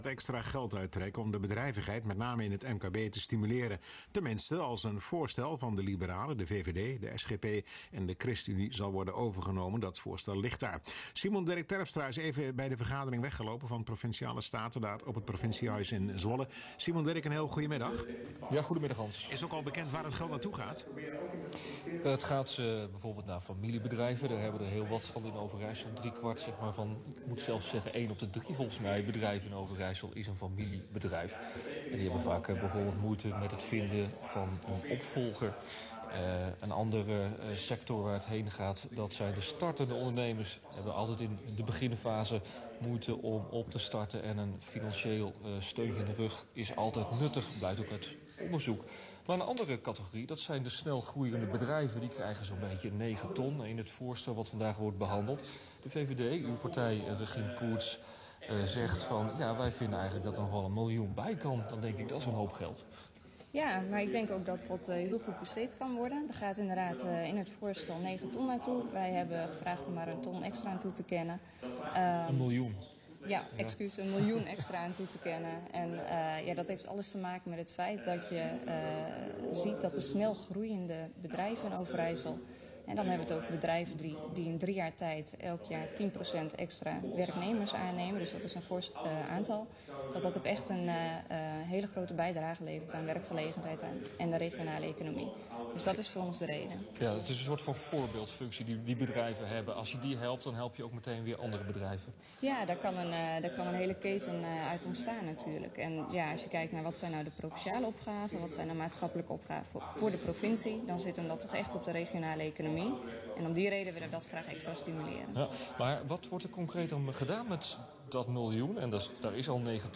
Luister hieronder naar een toelichting door initiatiefnemer Regien Courtz op RTV Oost.